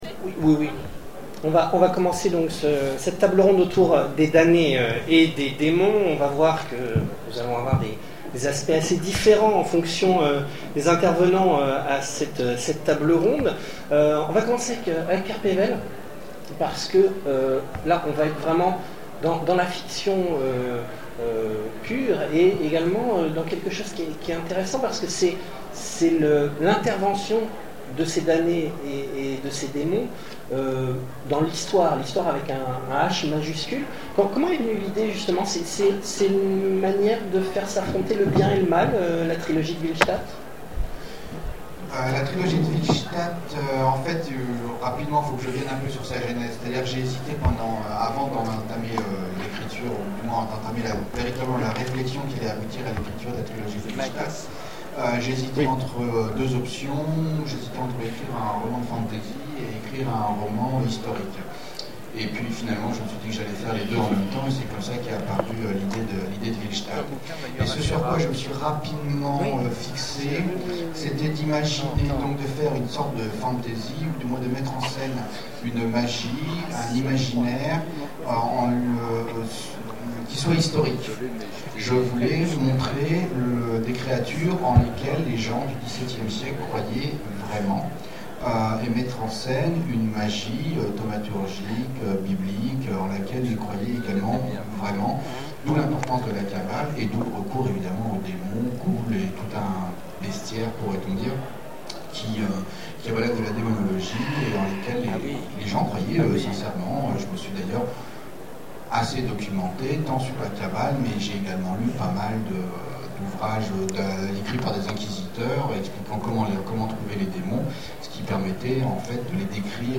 Zone Franche 2013 : Conférence Damnés et démons - ActuSF - Site sur l'actualité de l'imaginaire